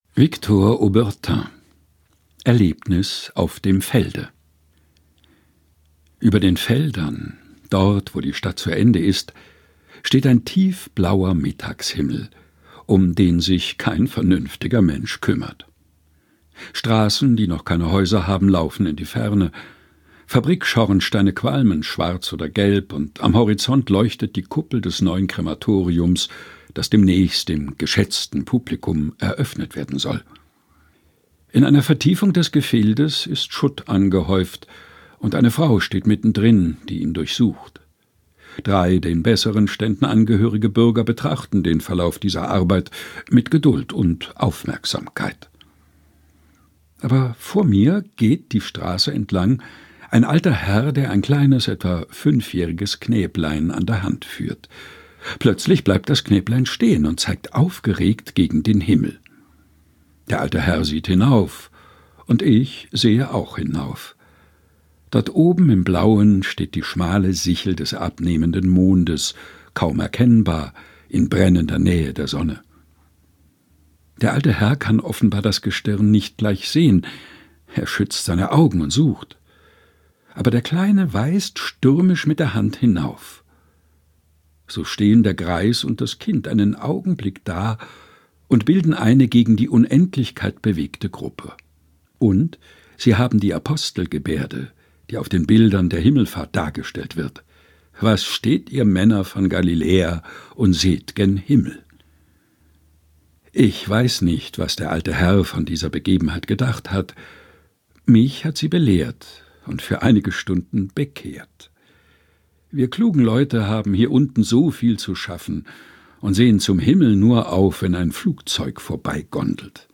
Texte zum Mutmachen und Nachdenken